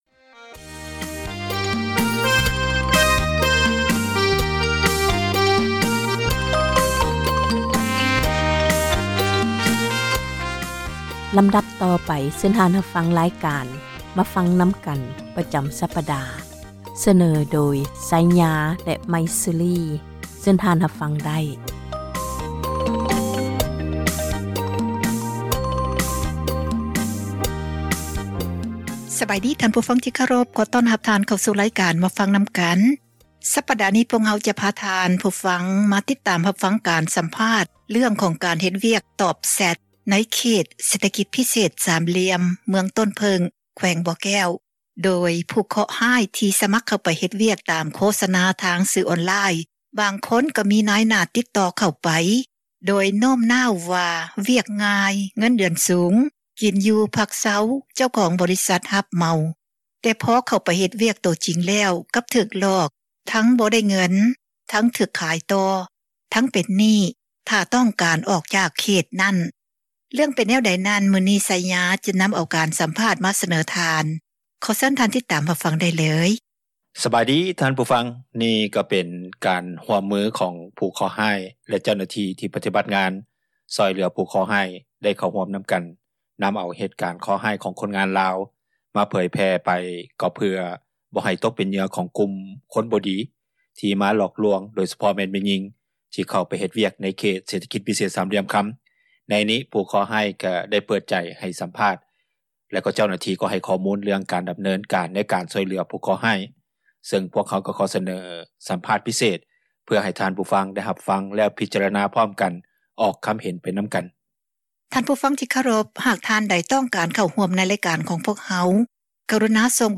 ຕອນປະກາດ ບອກວ່າ ເງິນເດືອນສູງ, ມີແຕ່ຕອບແຊັດ ກິນ, ຢູ່, ພັກເຊົາ ເຈົ້າຂອງຮັບ ທັງໝົດ. ແຕ່ພໍກ້າວຂາເຂົ້າໄປ ໃນເຂດ ກໍຖືກກັກກັນ, ຖືກຂາຍຕໍ່ ແລະ ເປັນໜີ້ເຈົ້າ ຂອງບໍຣິສັດອີກ. ເຣື່ອງນີ້ ພວກເຮົາ ໄດ້ສັມພາດ ພິເສດ ມາໃຫ້ຟັງ.